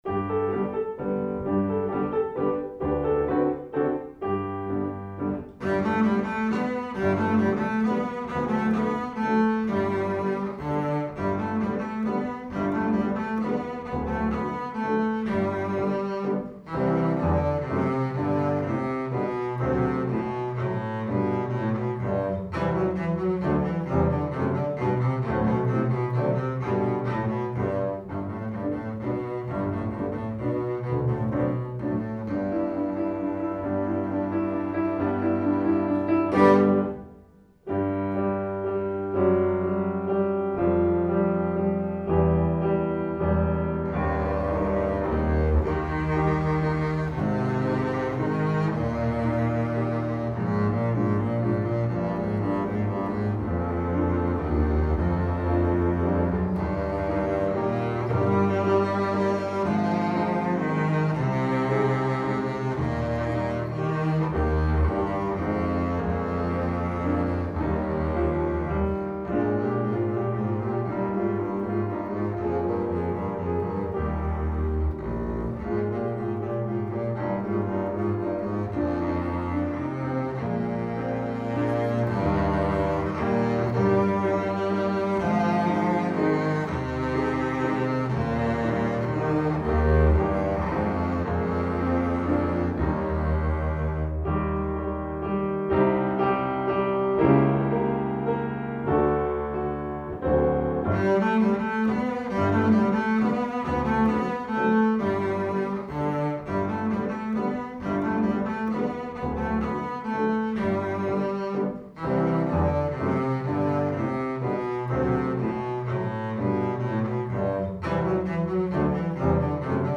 Voicing: String Bass Method w/ Audio